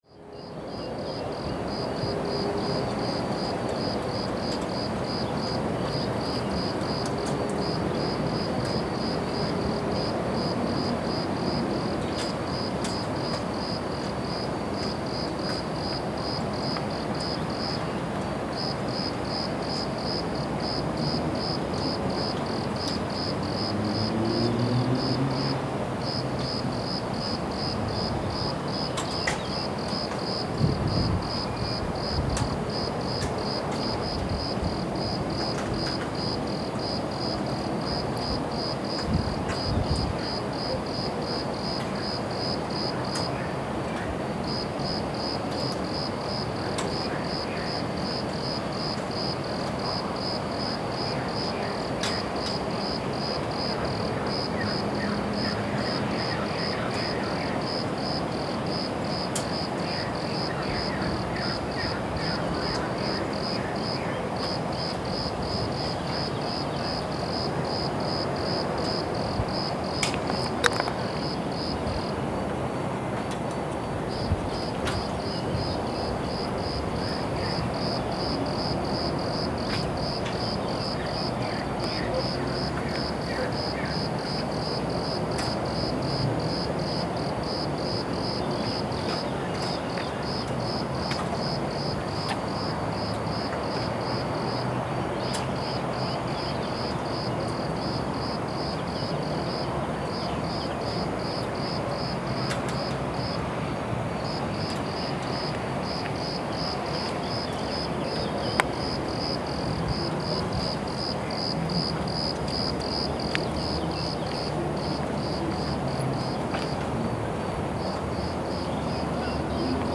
field recording
montpellier-evening.mp3